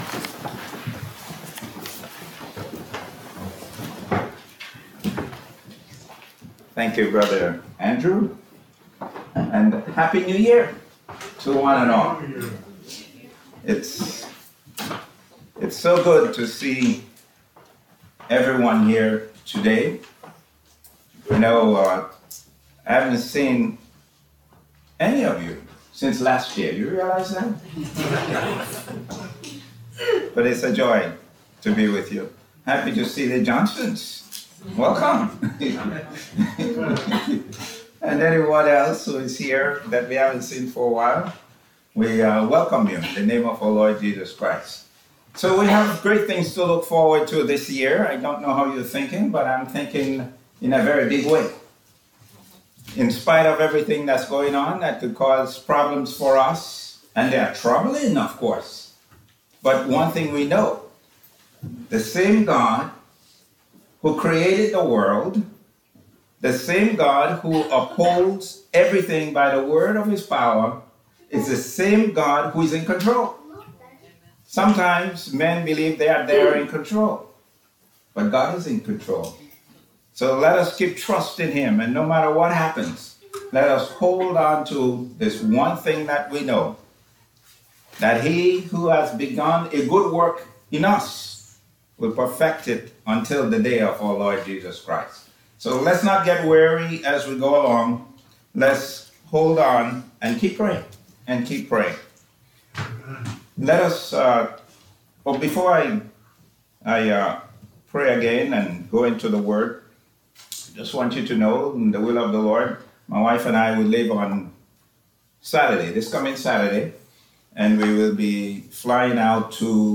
Passage: Matthew 24 Service Type: Sunday Afternoon « 12.25.22 – BC – A Merry Christmas with Jesus. 01.08.23 – JC – Blessing the lowest.